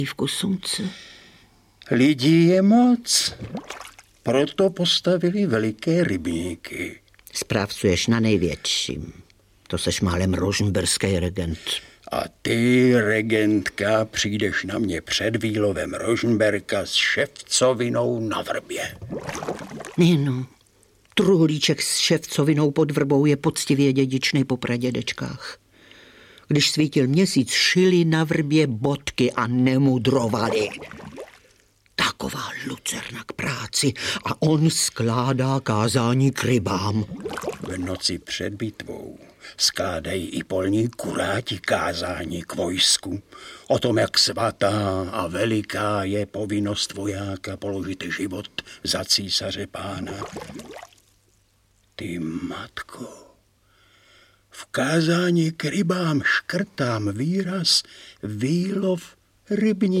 Audiobook
Read: Lubomír Lipský